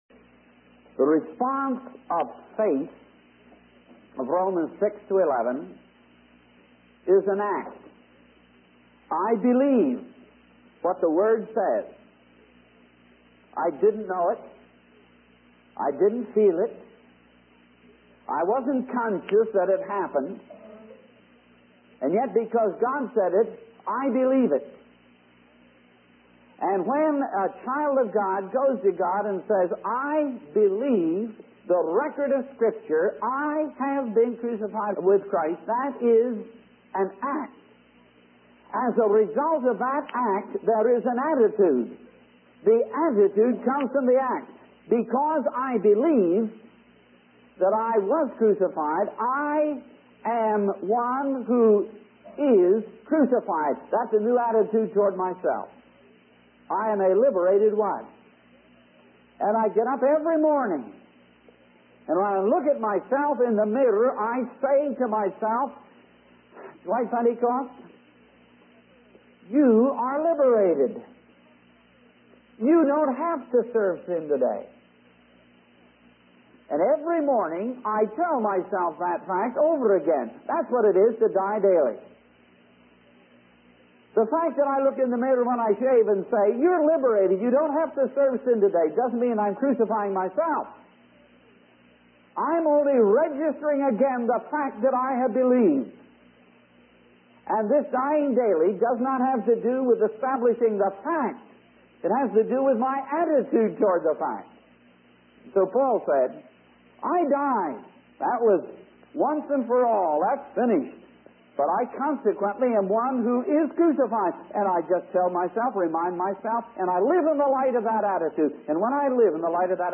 In this sermon, the preacher discusses the contrast between being controlled by the Holy Spirit and being controlled by the flesh. He explains that no one living under the law could keep the law because the flesh is weak.